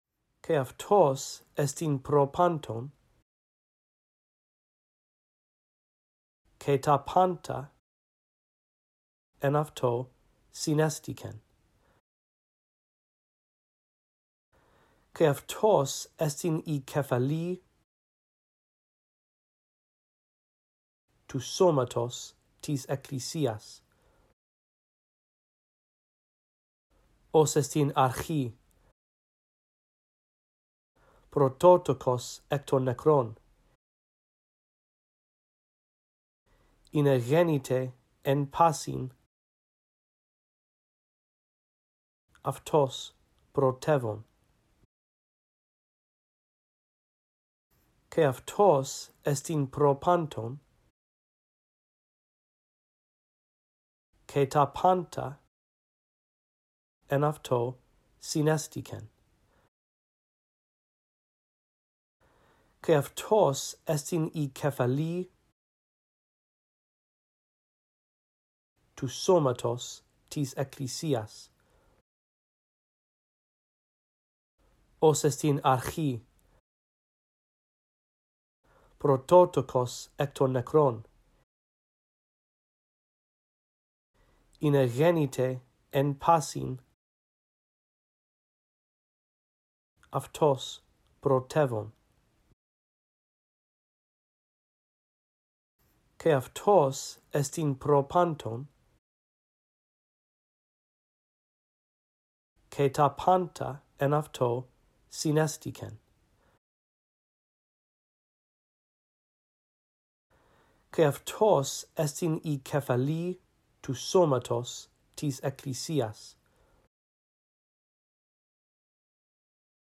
In this audio track, I read through verses 17-18 a phrase at a time, giving you time to repeat after me. After two run-throughs, the phrases that you are to repeat become longer.